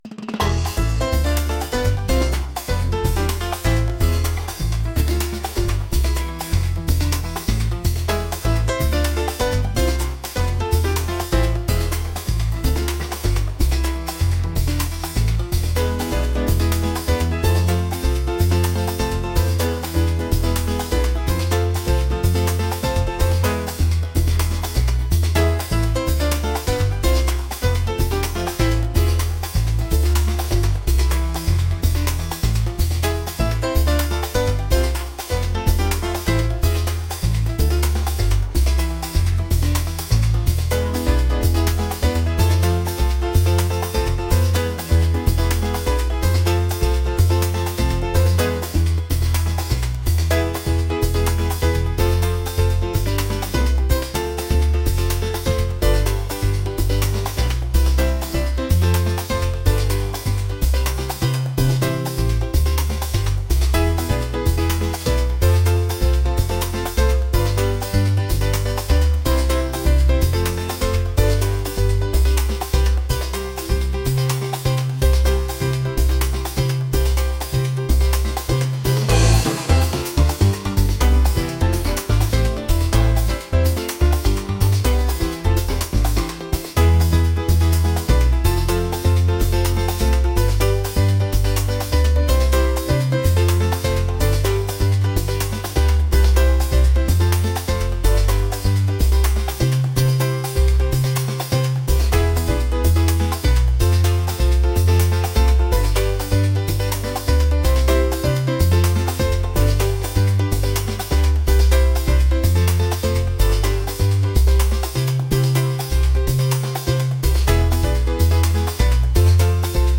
latin | rhythmic